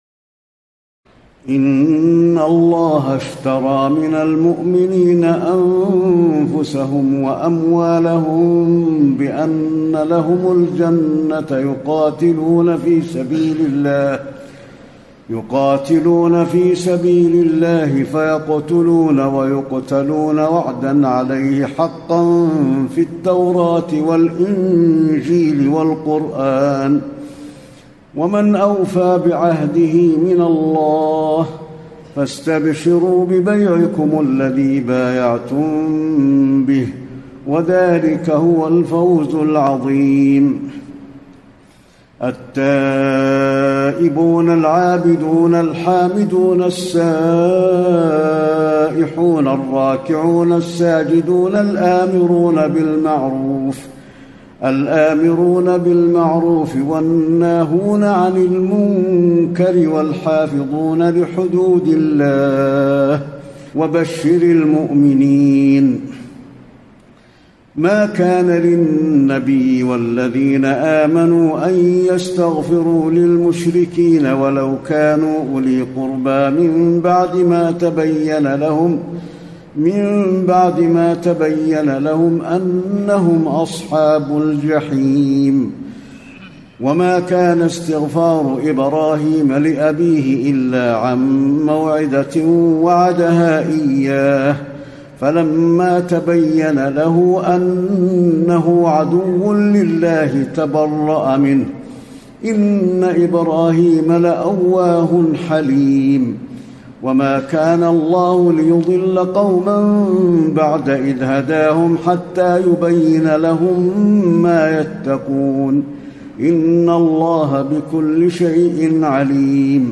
تراويح الليلة الحادية عشر رمضان 1434هـ من سورتي التوبة (111-129) و يونس (1-70) Taraweeh 11 st night Ramadan 1434H from Surah At-Tawba and Yunus > تراويح الحرم النبوي عام 1434 🕌 > التراويح - تلاوات الحرمين